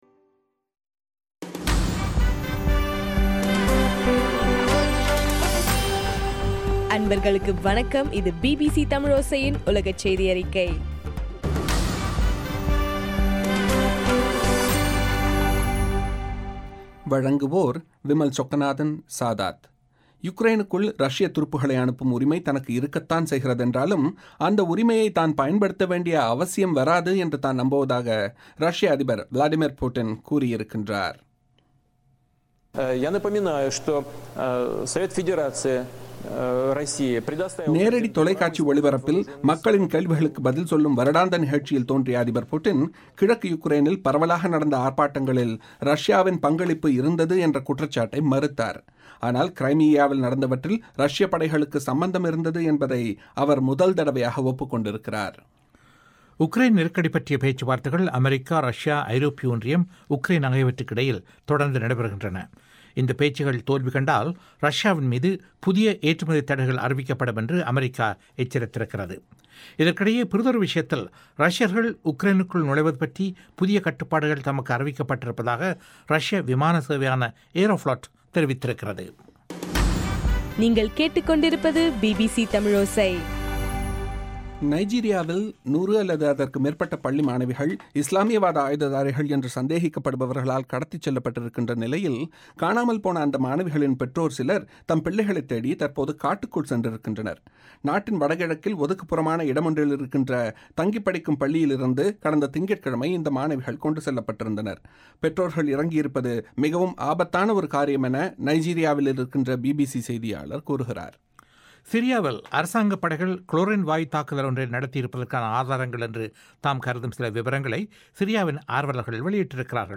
ஏப்ரல் 17 பிபிசியின் உலகச் செய்திகள்